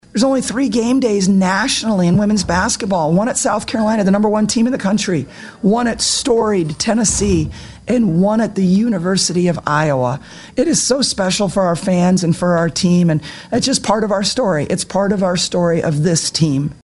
It capped a day that saw Carver-Hawkeye Arena host ESPN GameDay. Iowa coach Lisa Bluder.